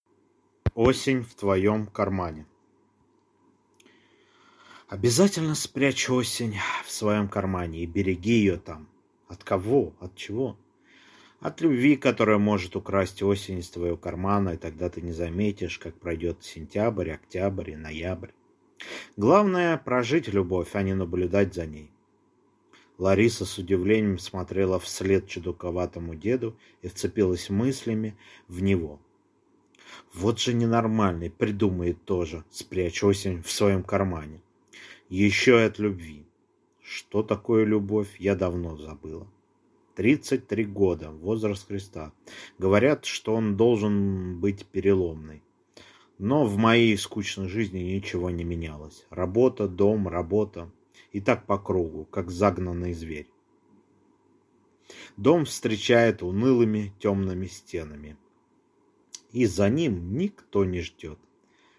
Аудиокнига Осень в твоём кармане | Библиотека аудиокниг